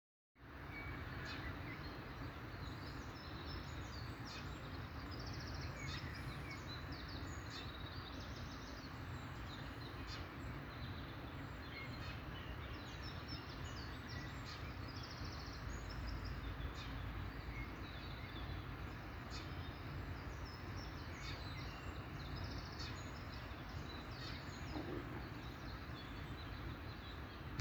Là, vous pouvez entendre le chant des oiseaux tôt le matin (26.3.2021)
Ces 30 arbres présentent déjà une taille permettant de fixer 15 nouveaux nichoirs. Là, vous pouvez entendre le chant des oiseaux tôt le matin lorsque je quitte la maison pour rejoindre l’entreprise en empruntant le chemin de 100 mètres à travers les arbres.